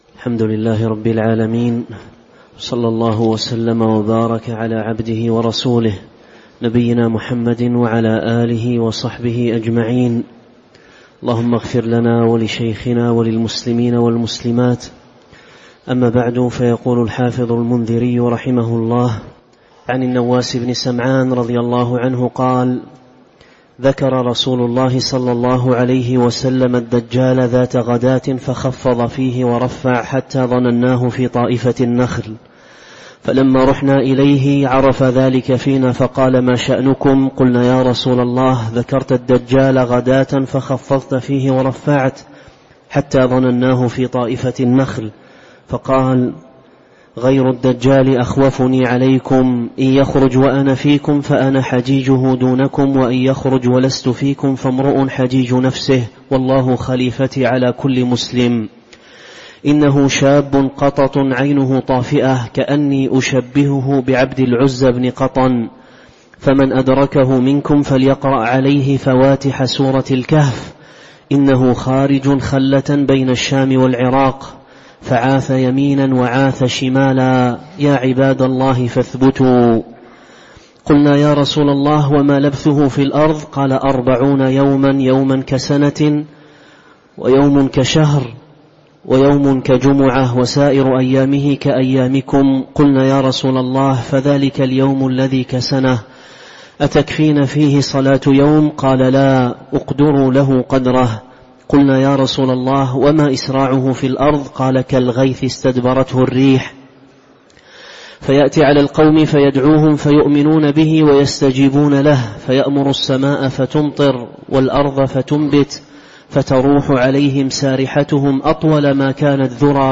تاريخ النشر ١٣ صفر ١٤٤٤ هـ المكان: المسجد النبوي الشيخ